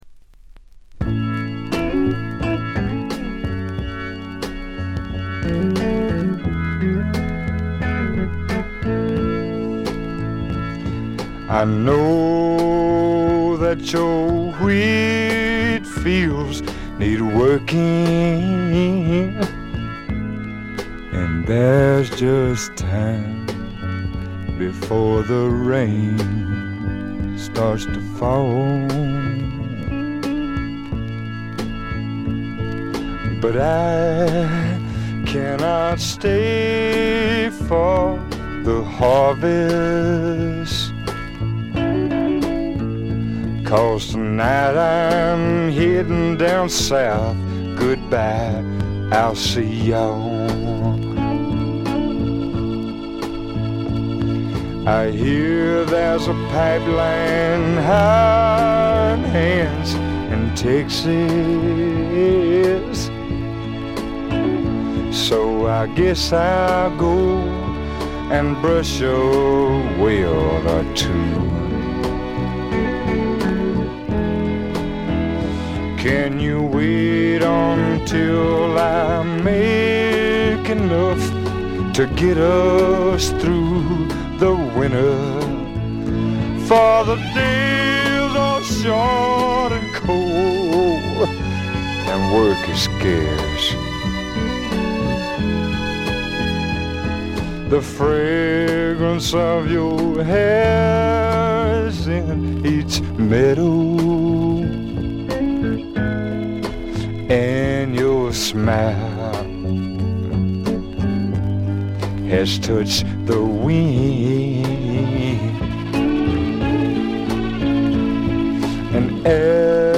B面最後音が消える4秒前ぐらいから「ザッ」というノイズ。
試聴曲は現品からの取り込み音源です。